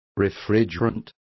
Complete with pronunciation of the translation of refrigerants.